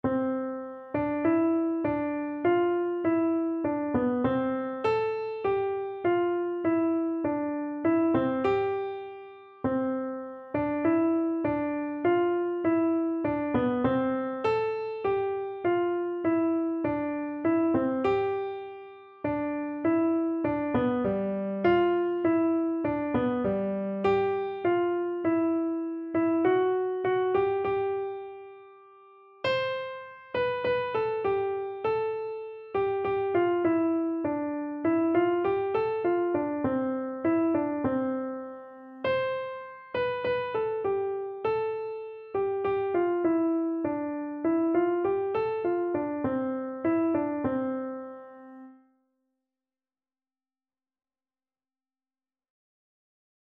Franz Josef Haydn - Deutschlandlied (German National Anthem) Free Sheet music for Keyboard (Melody and Chords)
german_nat_anth_KYB.mp3